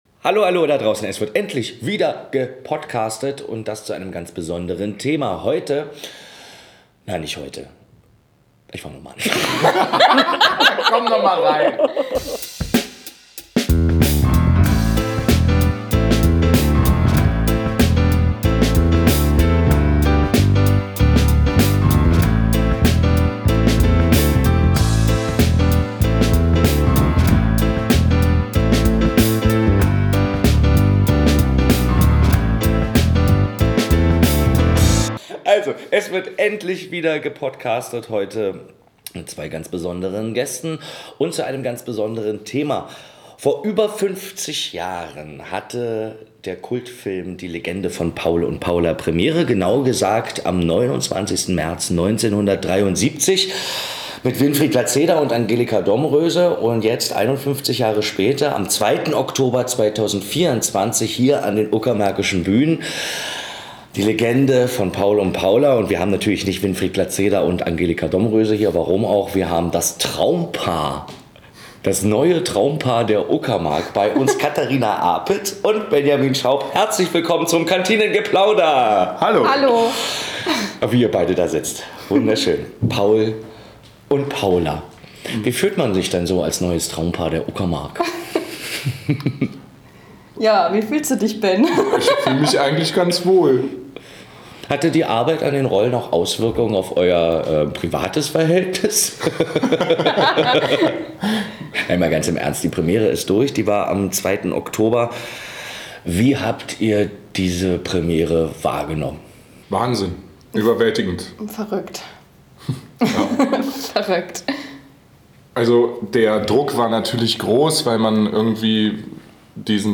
Die drei reden über DDR-Musik und ihre Lieblingssongs aus der Inszenierung – angefangen bei „Komm doch mit“ bis hin zu „Wo bist du“. Sie nähern sich den Unterschieden zwischen Film und Bühne und denen zwischen der großen Show und den stillen Zweierszenen zwischen Paul und Paula.